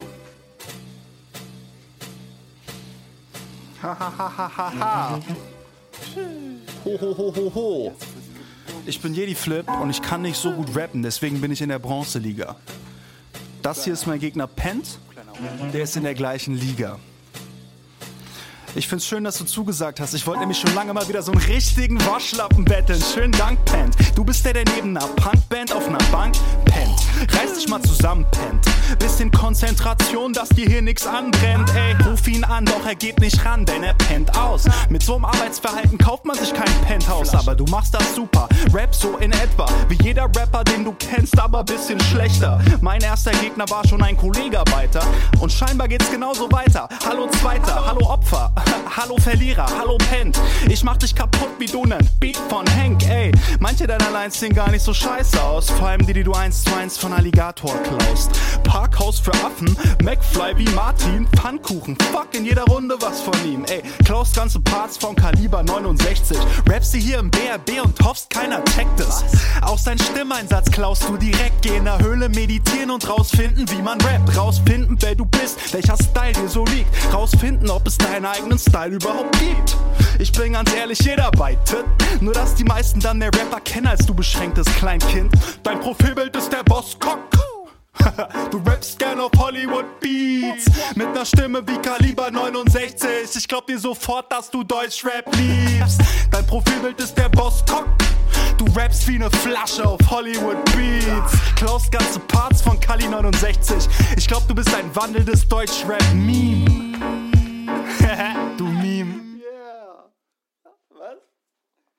Was ich bei dir cool finde ist wie sauber und klar du betonst.
Stabiler Flow.